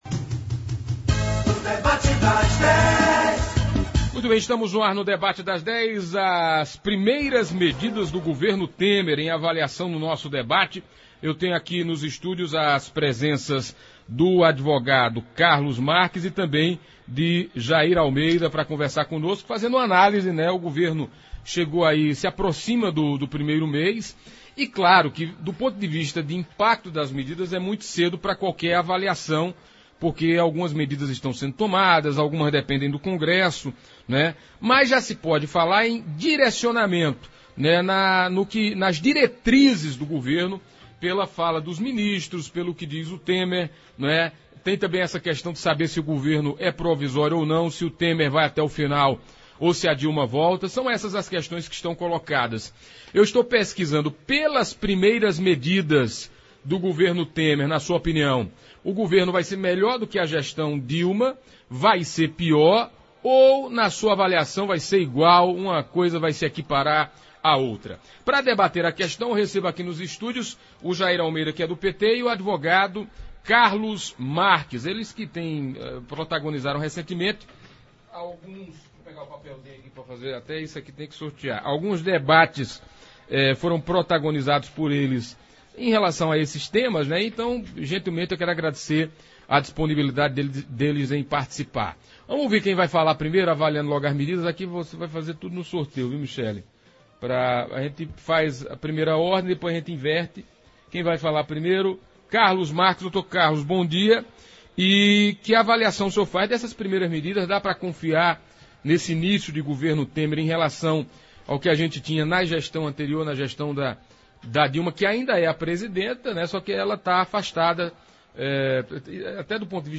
O debate seguiu com cada um defendendo as suas posições, respondendo a questionamentos e ouvindo testemunhos dos leitores e internautas da Pajeú que em sua grande maioria disseram não acreditar que o governo de Temer conseguirá tirar o país da situação que se encontra e que também acreditam que será pior do que o governo de Dilma.